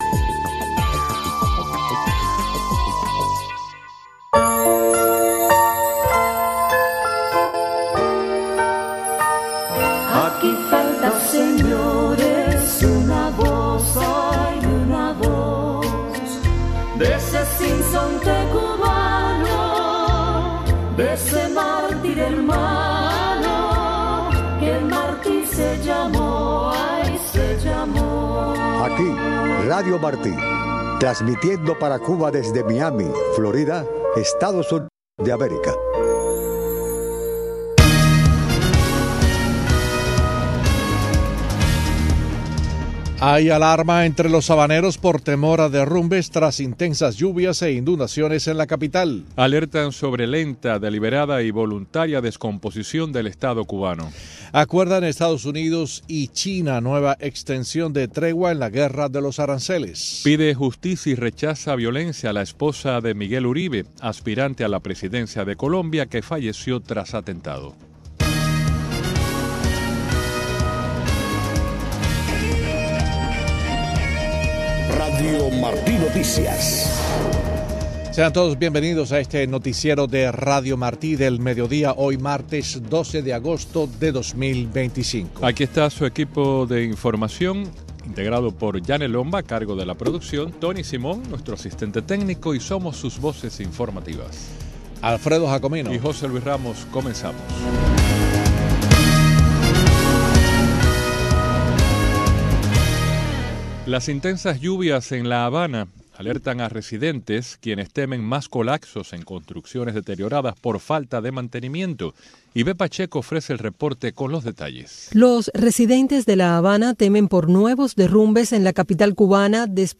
Embed share Noticiero de Radio Martí 12:00 PM Embed share The code has been copied to your clipboard.